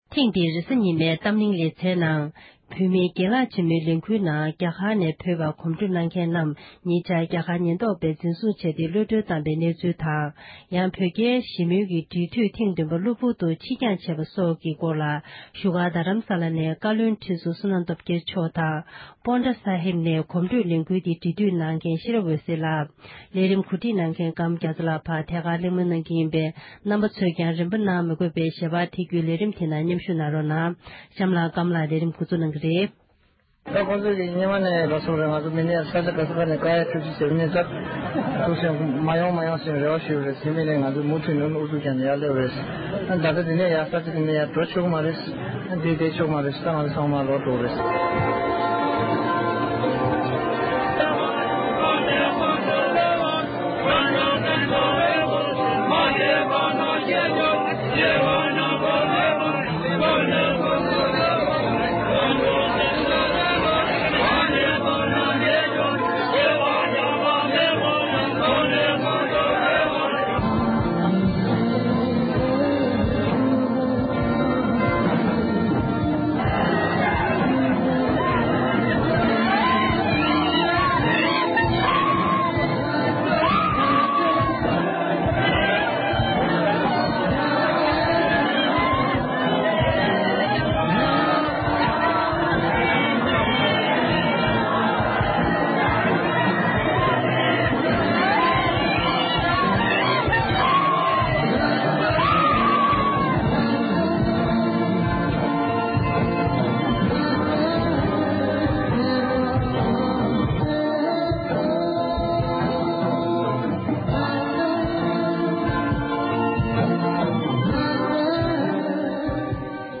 བོད་རྒྱའི་འབྲེལ་མོལ་དང་བོད་བར་གྱི་ཞི་བའི་གོམ་བགྲོད་ཀྱི་ལས་འགུལ་སྐོར་གླེང་བ།